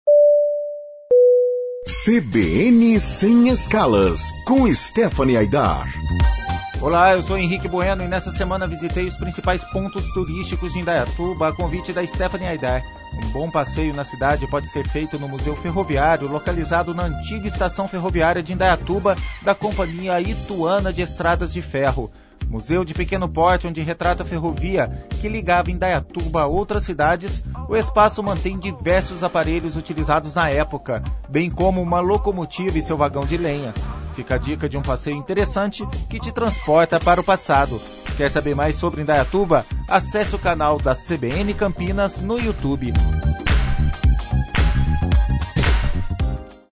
O News Truck da CBN Campinas esteve em Indaiatuba, nas comemorações do aniversário de 189 anos da cidade.